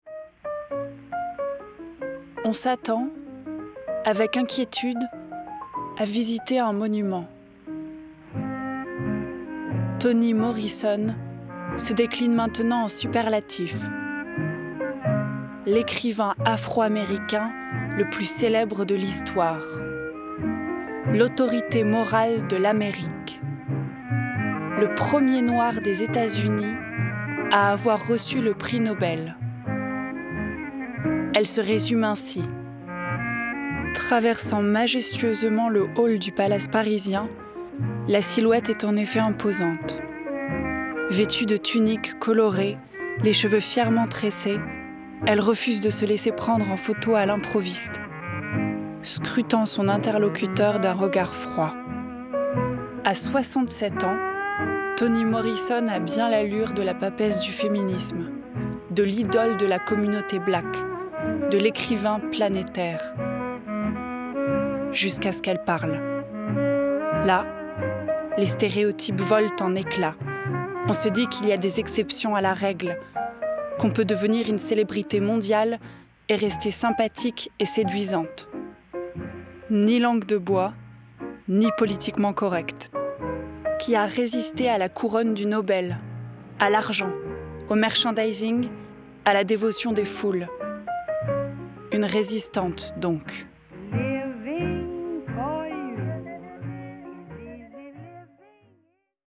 Docu Voix off
Narratrice
- Soprano